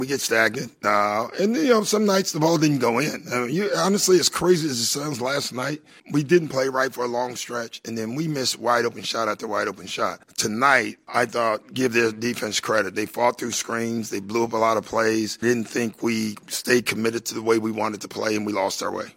Rivers talked about why the offense is struggling.